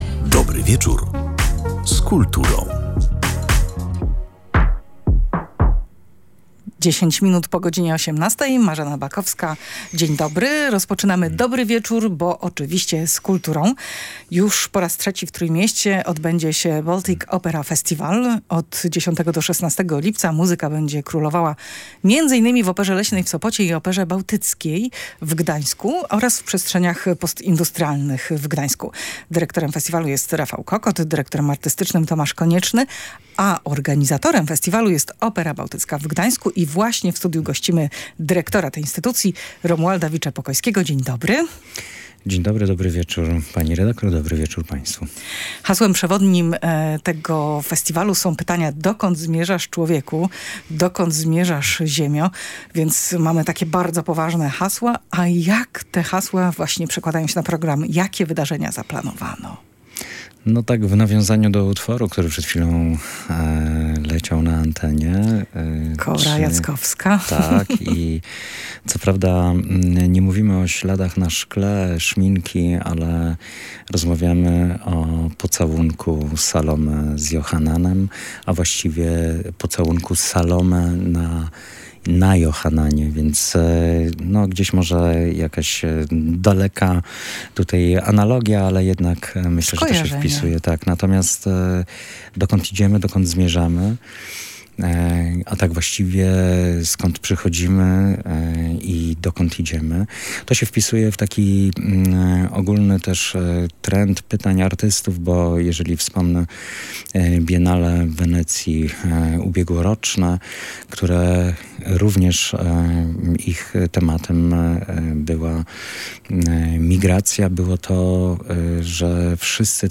gość audycji „Dobry wieczór z kulturą”